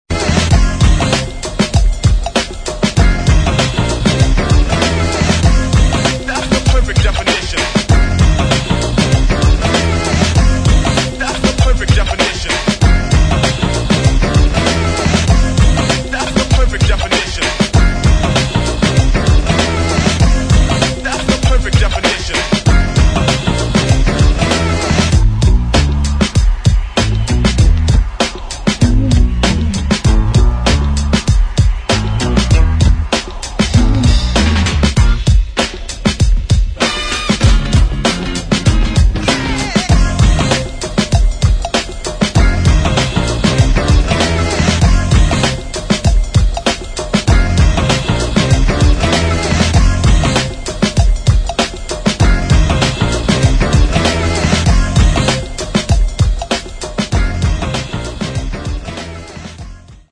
[ HIP HOP ]
Instrumental